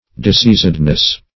Search Result for " diseasedness" : The Collaborative International Dictionary of English v.0.48: Diseasedness \Dis*eas"ed*ness\, n. The state of being diseased; a morbid state; sickness.